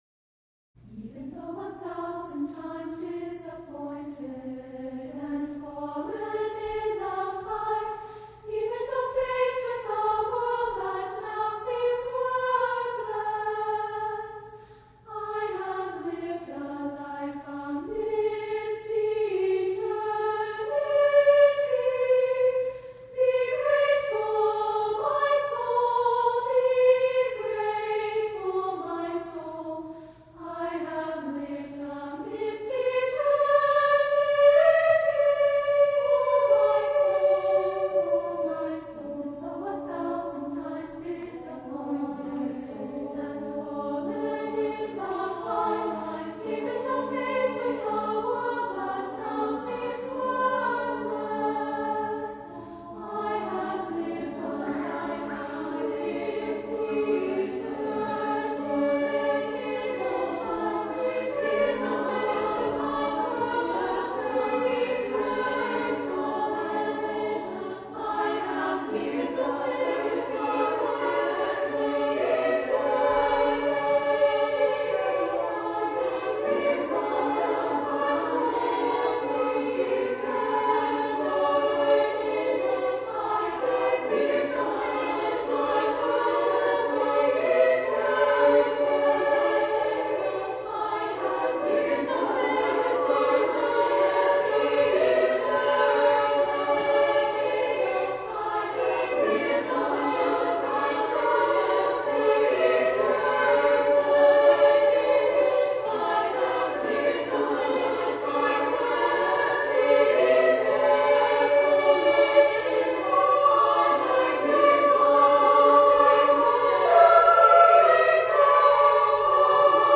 SSAA a cappella OR 5-part canon